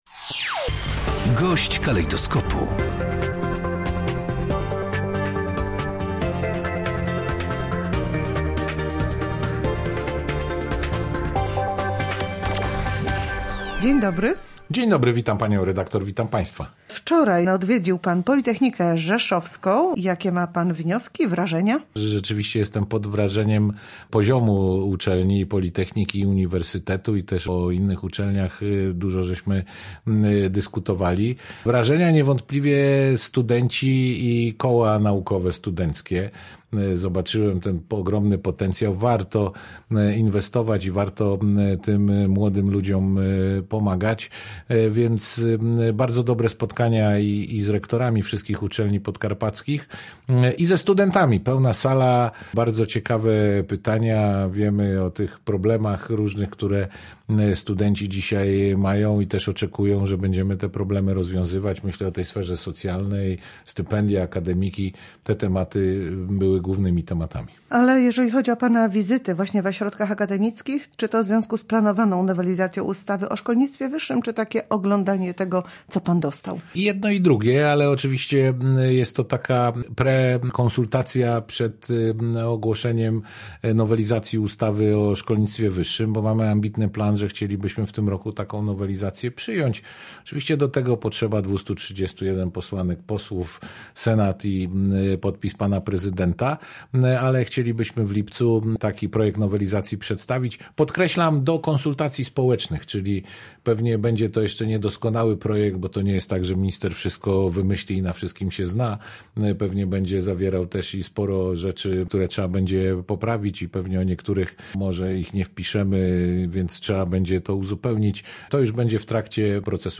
Rektorzy mają otrzymać większe uprawnienia. Gość Radia Rzeszów zwrócił też uwagę na to, że promowane będzie łączenie się uczelni w regionie.